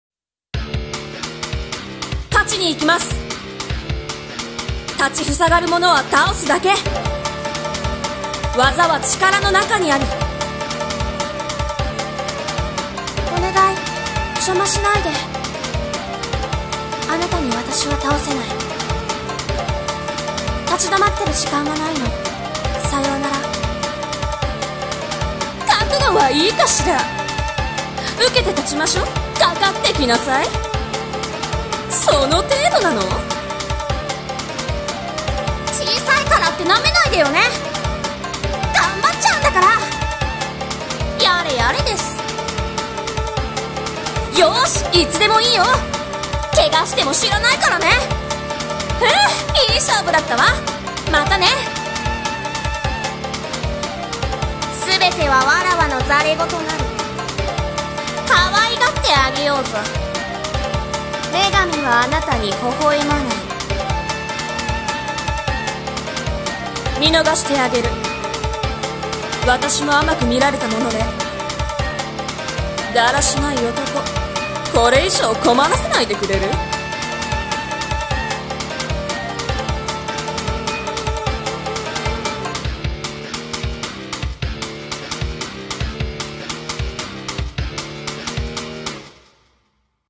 【演じ分け】『格闘ゲーム風台詞集】女ver.